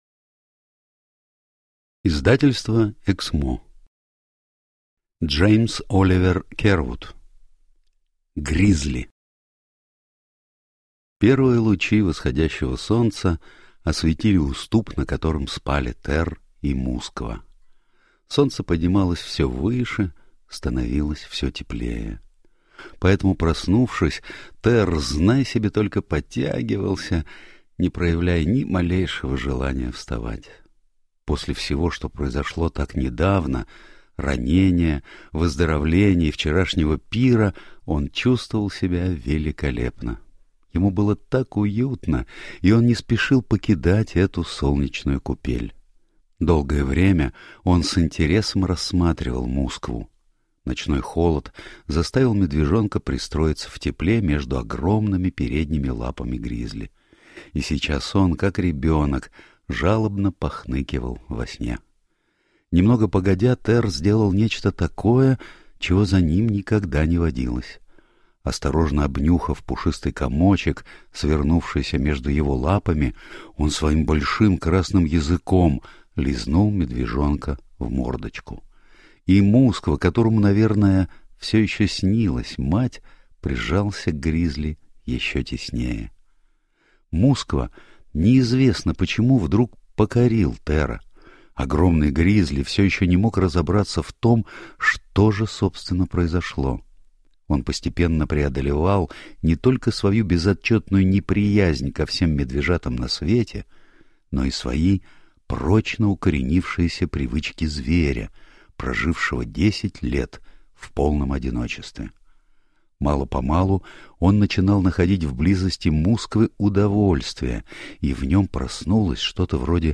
Аудиокнига Гризли | Библиотека аудиокниг